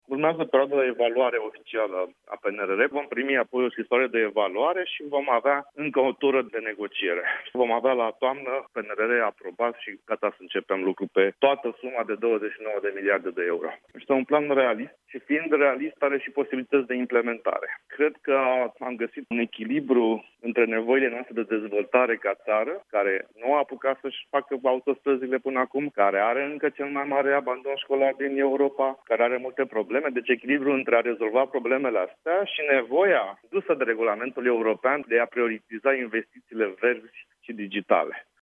Va urma, apoi, la Bucureşti, o prezentare a Planului Naţional de  Redresare şi Rezilienţă, spune – la Europa FM – ministrul Fondurilor Europene.